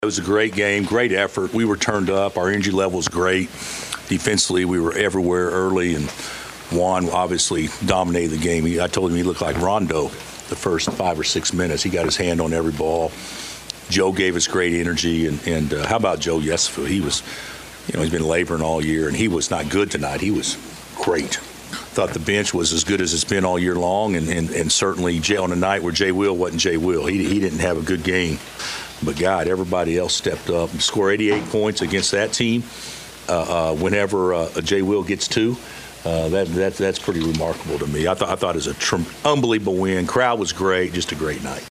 Coach Bill Self called it a great team win.